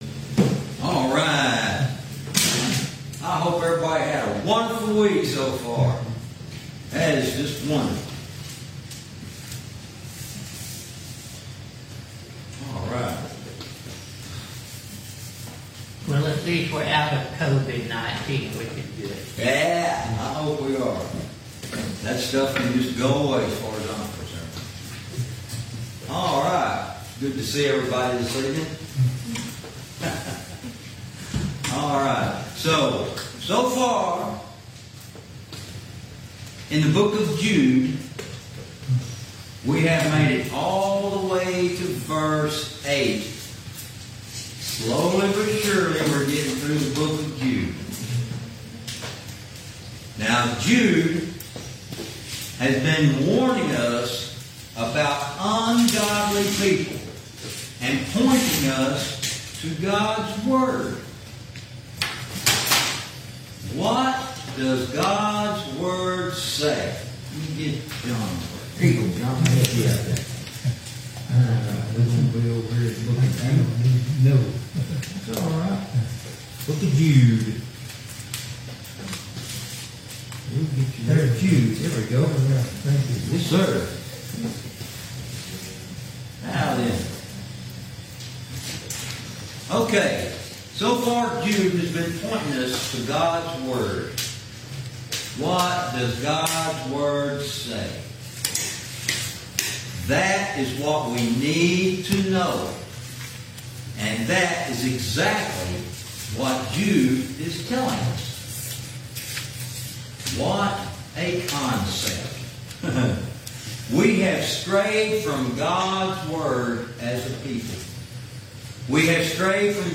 Verse by verse teaching - Lesson 29